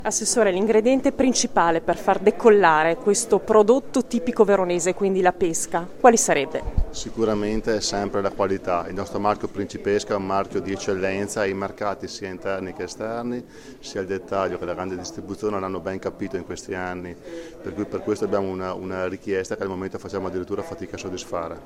Al microfono della nostra corrispondente
l’assessore all’Agricoltura di Bussolengo, Giovanni Amantia